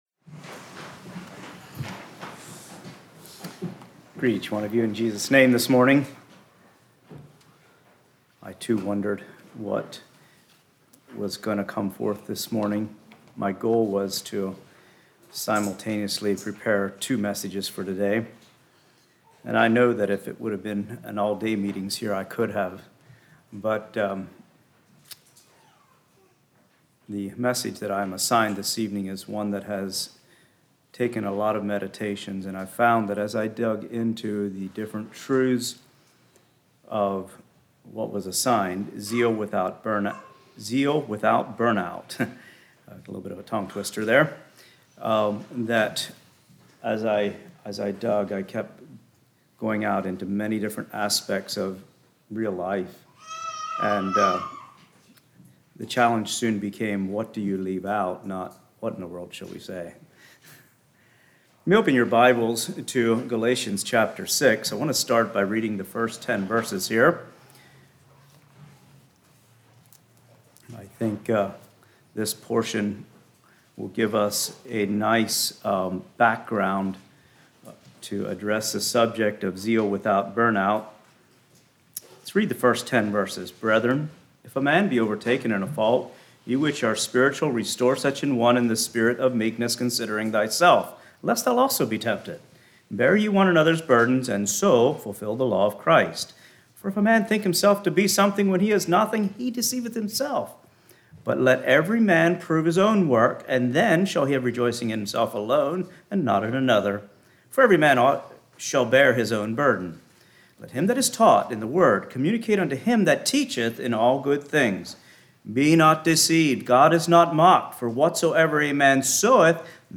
Play Now Download to Device Zeal Without Burnout Congregation: Tyrone Speaker